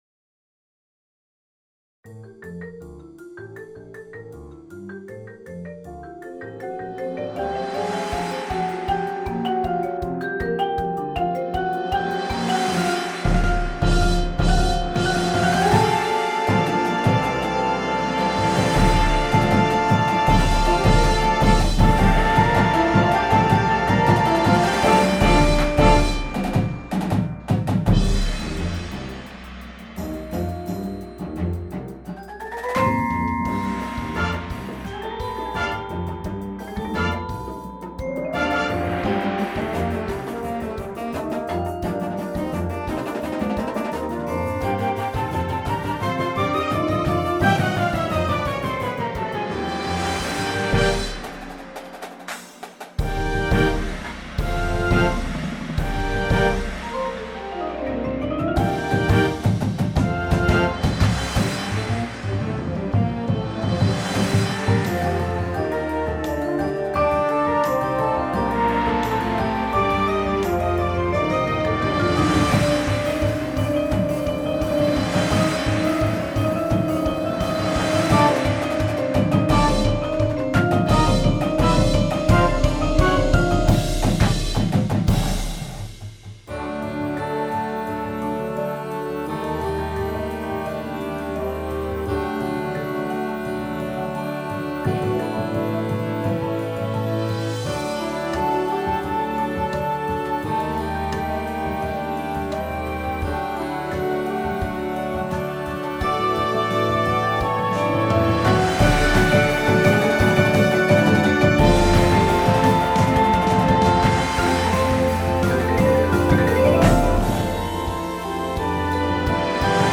• Flute
• Alto Saxophone
• Trumpet 1, 2
• Trombone 1, 2
• Tuba
• Snare Drum
• Bass Drums